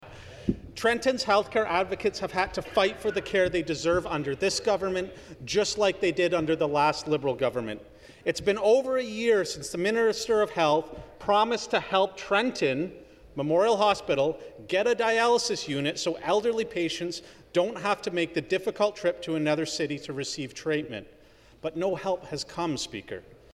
A conservative MPP, speaking for the Minister of Health Christine Elliott, answered that the government is developing a four-pillar plan to address hallway health care.